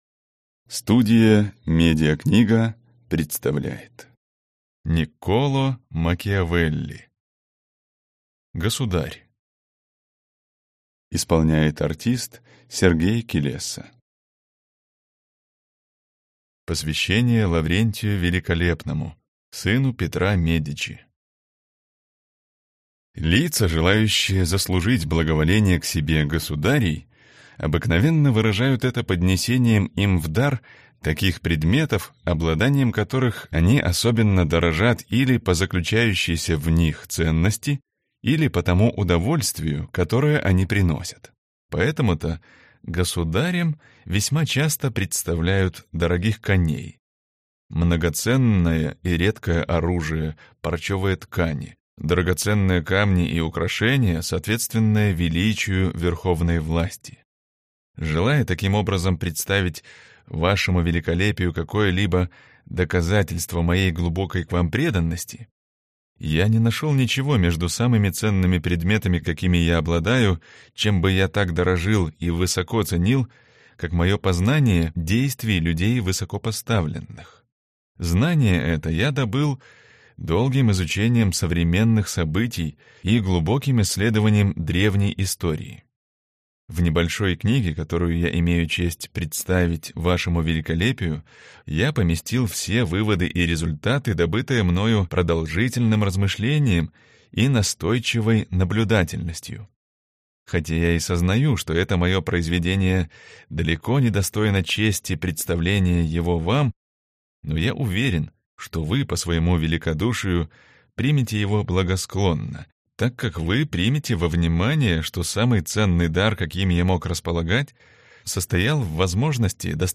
Аудиокнига Государь | Библиотека аудиокниг